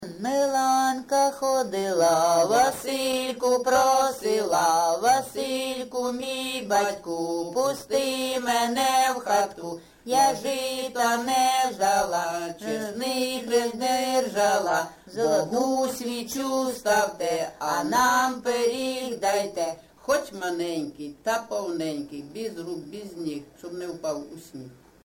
ЖанрЩедрівки
Місце записум. Сіверськ, Артемівський (Бахмутський) район, Донецька обл., Україна, Слобожанщина